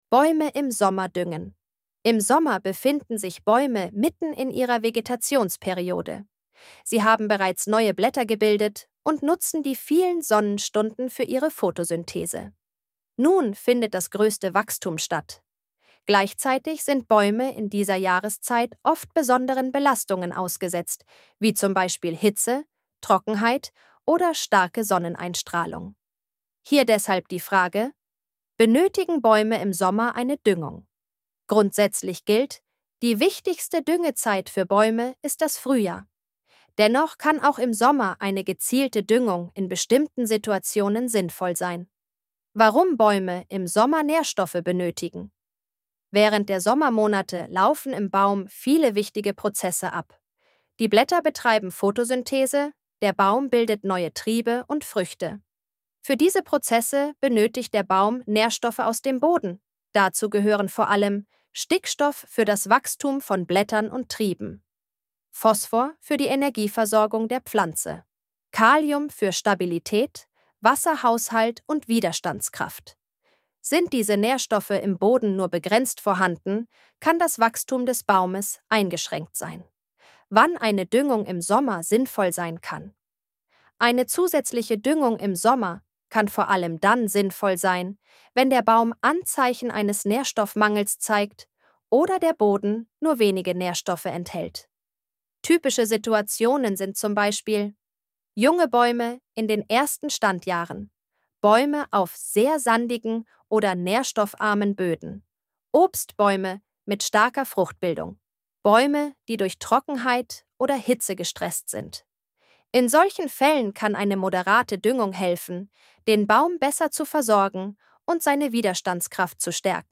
von Team baumbad 25.03.2026 Artikel vorlesen Artikel vorlesen Im Sommer befinden sich Bäume mitten in ihrer Vegetationsperiode.